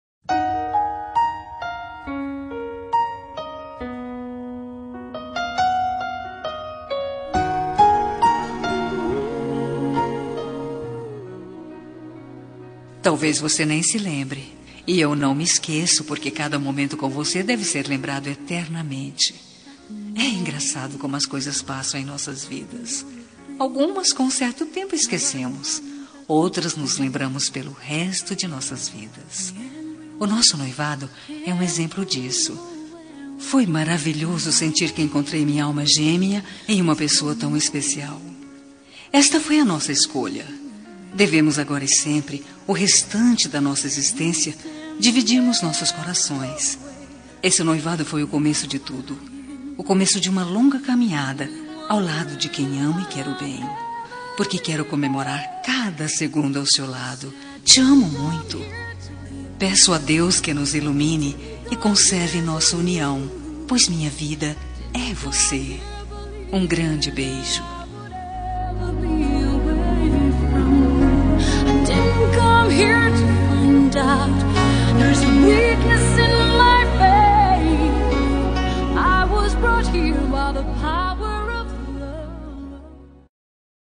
Homenagem ao Noivo – Voz Feminina – Cód: 315